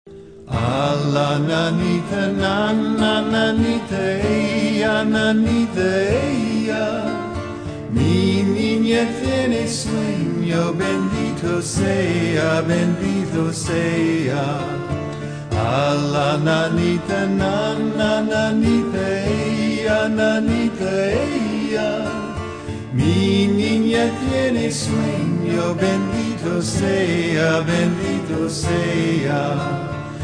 Spanish Folk Melody